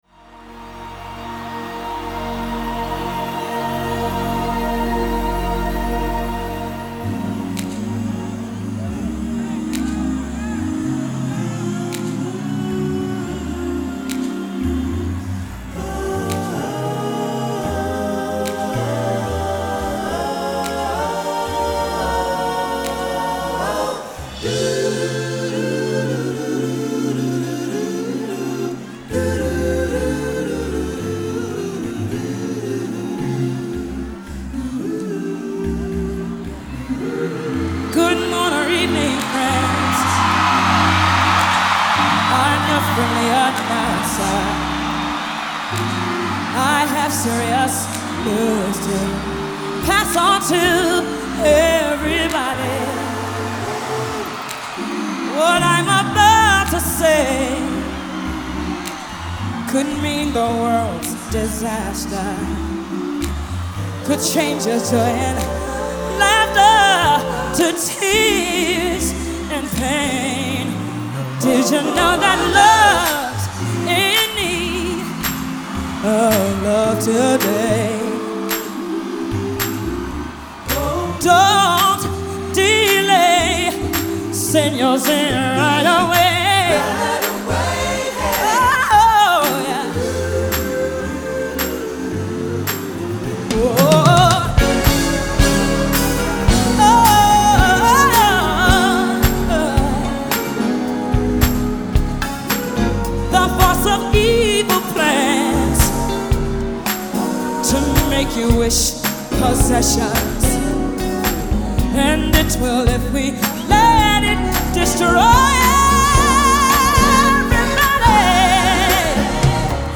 Pop / R&B / Live